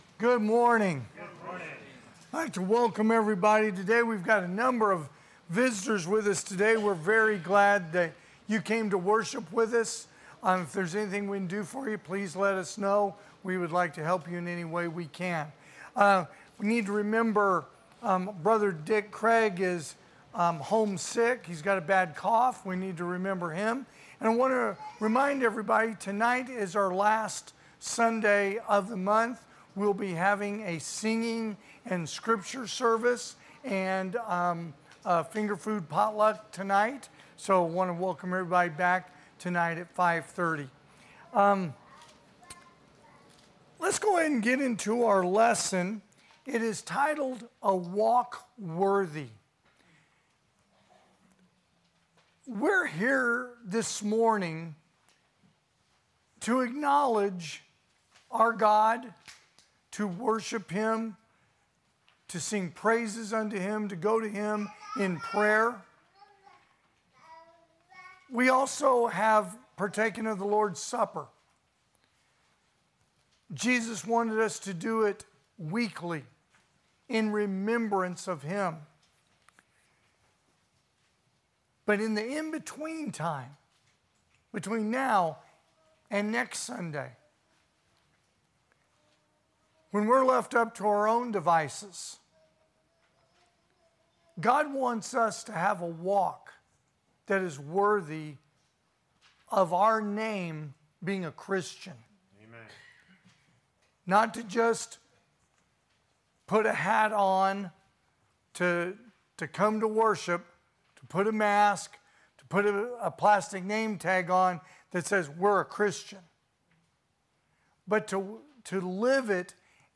2024 (AM Worship) "A Walk Worthy"